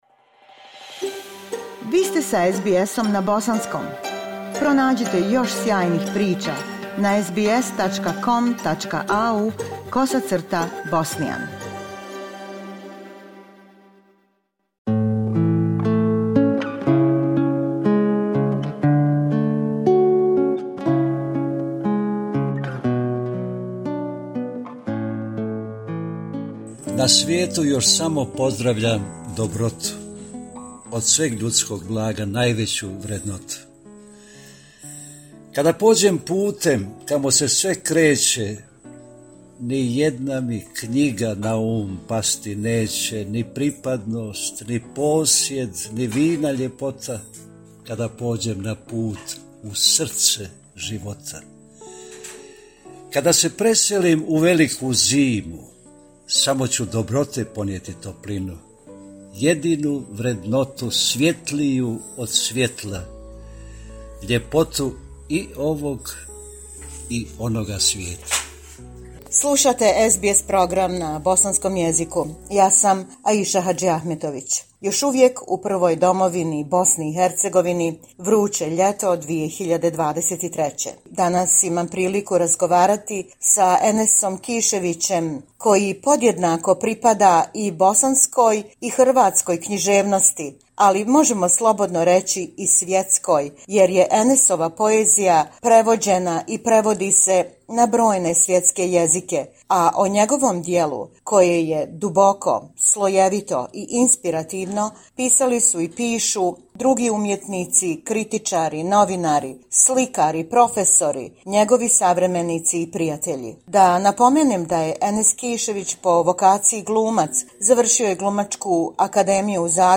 Razgovor s najvećim pjesnikom ljepote i dobra u čovjeku, Enesom Kiševićem, koji živi i diše svoju poeziju. Više od pedeset godina nastanjen u Zagrebu, ali još uvijek dubokim nitima vezan za Bosnu.
Pjesnik Enes Kišević, Otoke, Sanski Most, 3. august 2023. godine